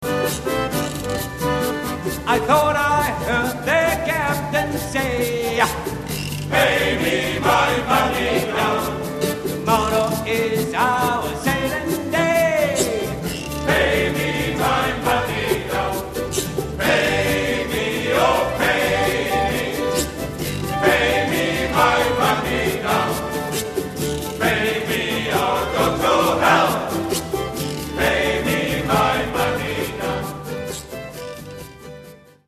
Interpret: Hart Backbord Shanties
Musik: trad.